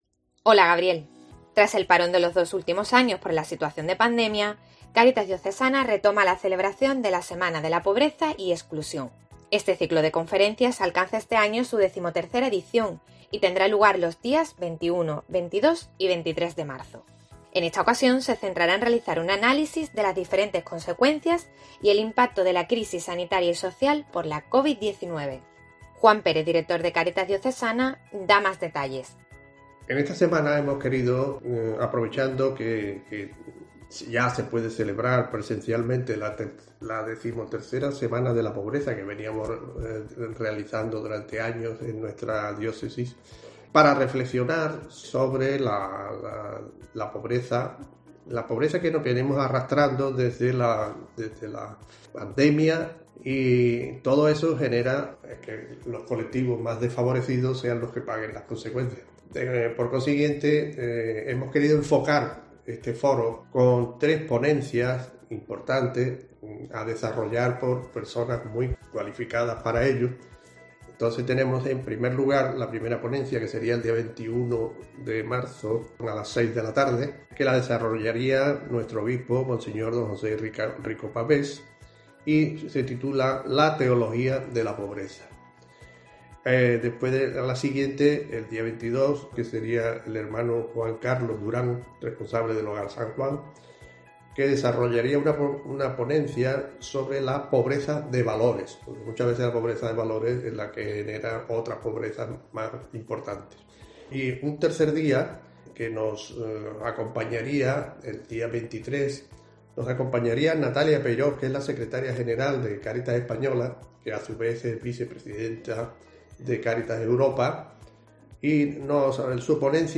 En sus palabras se pulsa incluso el entusiasmo propio de quienes, al fin y tras dos años en los que la pandemia ha condicionado la normal celebración del ciclo, llega la posibilidad del tradicional encuentro en el Obispado que no solo permite la escucha sino la interactuación con los ponentes.